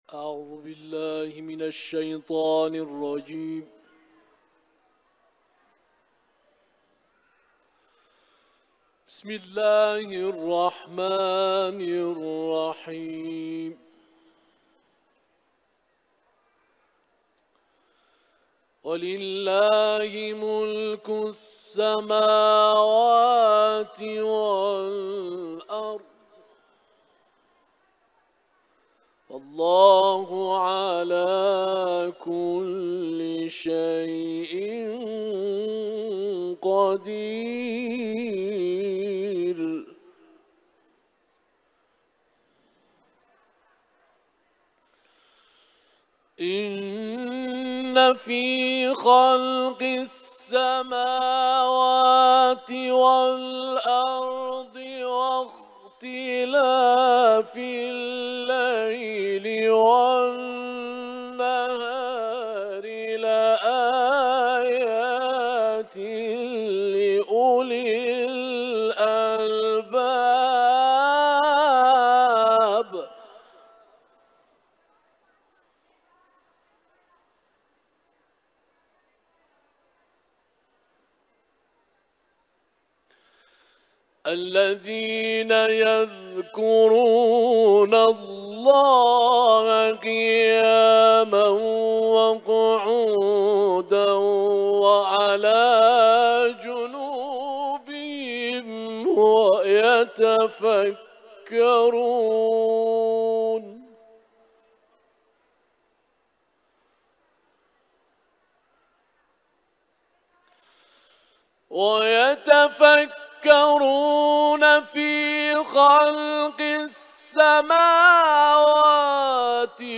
تلاوت آیات ۱۸۹ تا ۱۹۵ از سوره مبارکه «آل‌عمران»
در جوار بارگاه منور حضرت رضا(ع) اجرا شده است
تلاوت قرآن